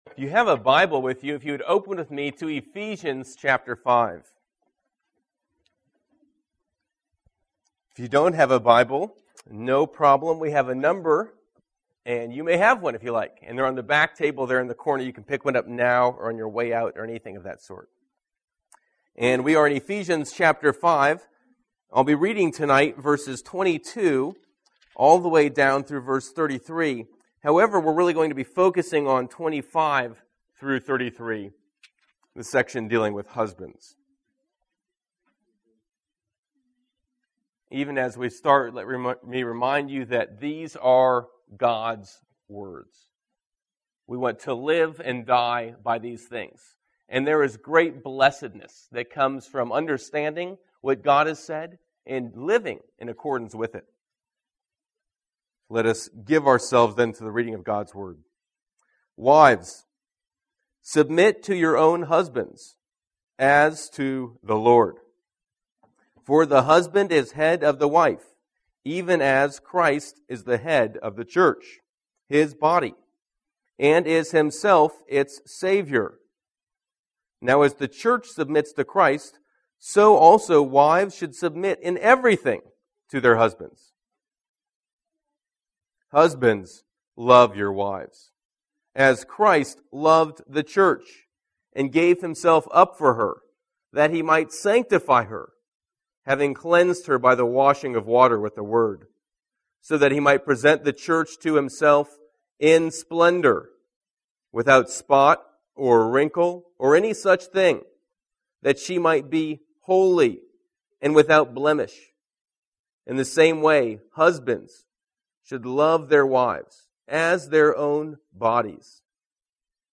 Sermons - The Gospel in Our Homes < King of Kings, PCA